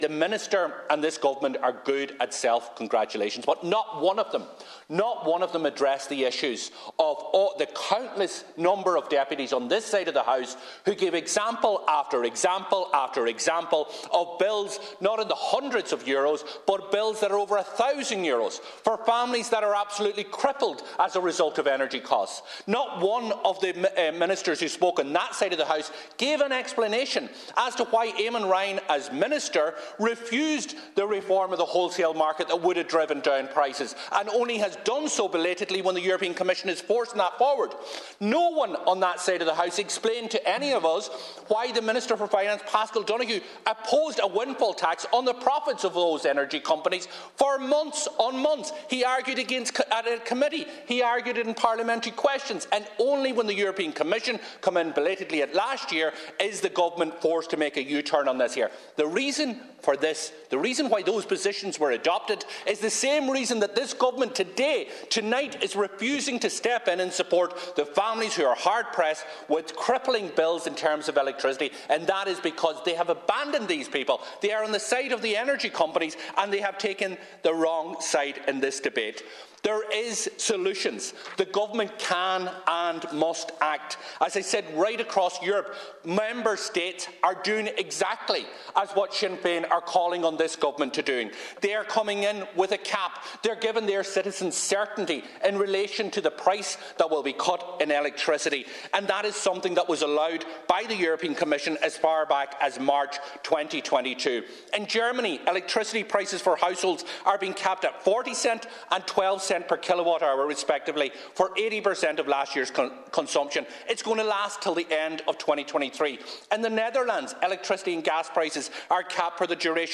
Donegal Deputy Pearse Doherty has once again told the Dail that people right across the country are being crippled by high energy costs.
He told Minister Michael McGrath that there are solutions if the Government is willing to act: